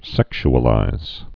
(sĕksh-ə-līz)